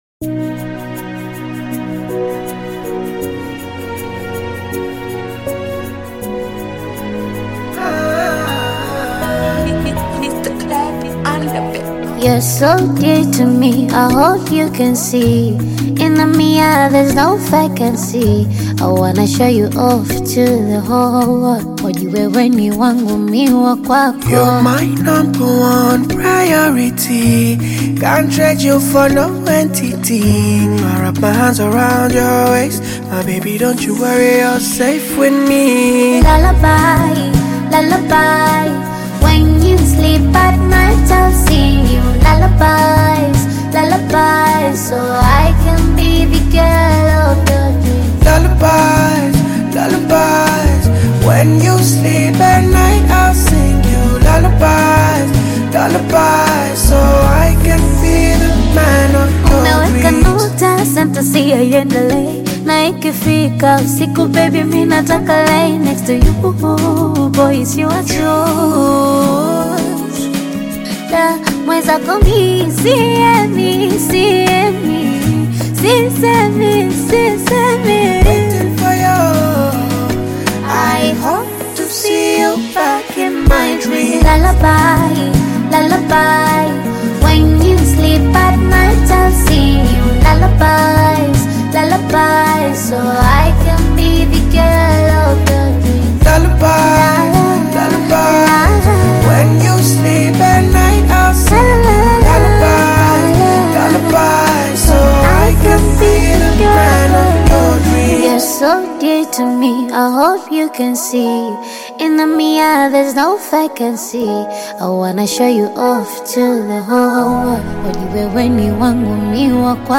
soothing Afro-Pop/Bongo Flava collaboration